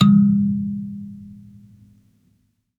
kalimba_bass-G#2-pp.wav